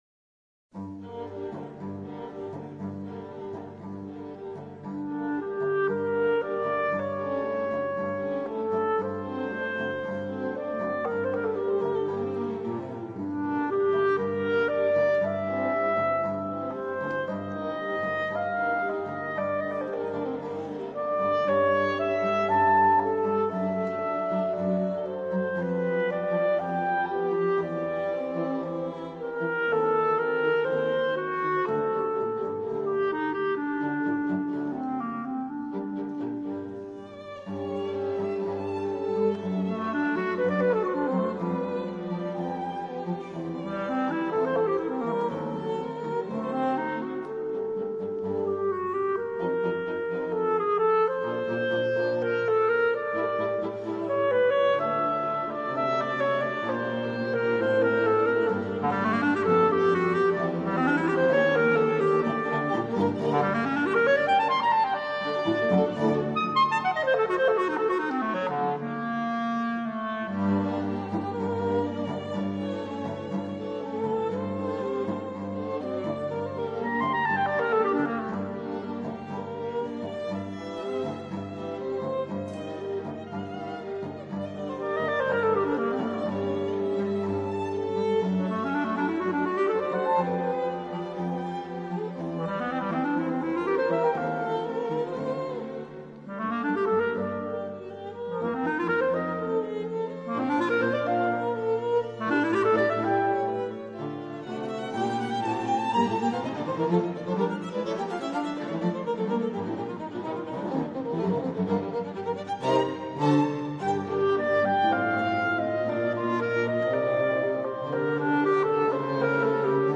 per clarinetto in Bb e quartetto d’archi
Clarinetto e quartetto d'archi
World Premiere DENVER 5 Luglio 2023